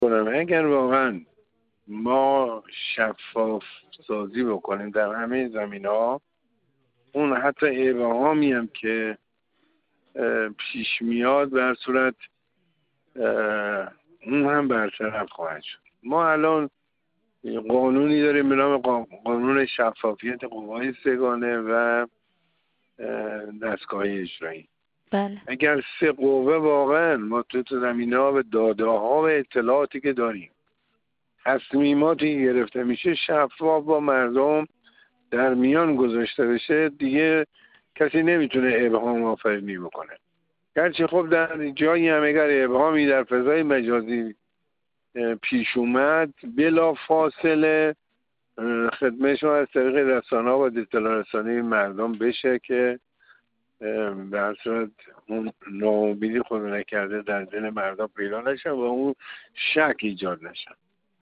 در این راستا محمدصالح جوکار، رئیس کمیسیون امور داخلی کشور و شوراهای مجلس شورای اسلامی، در گفت‌وگو با ایکنا درباره توصیه معظم له و لزوم رعایت چارچوب‌های نقادی، گفت: ما توانستیم با وحدت در طول تاریخ انقلاب از تمام مشکلات عبور و گذر کنیم و انقلاب را با همین وحدت به پیروزی برسانیم.